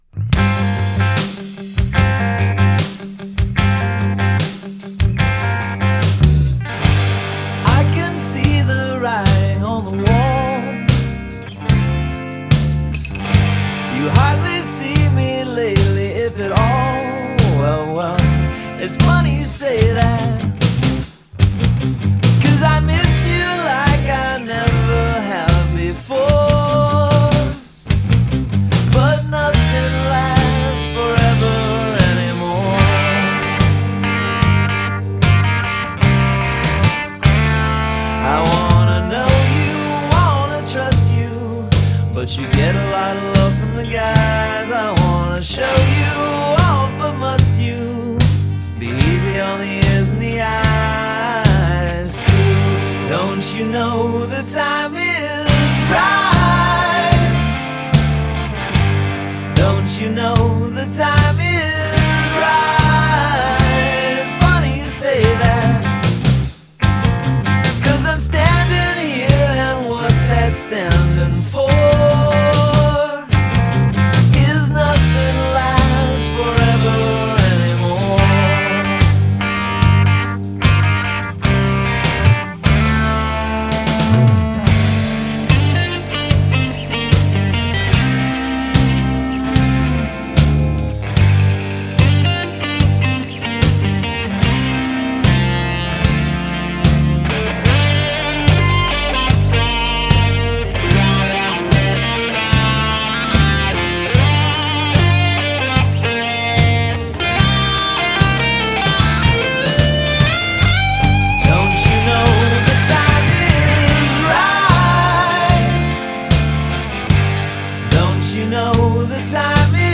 Count the guitar tones used here
Canadian power pop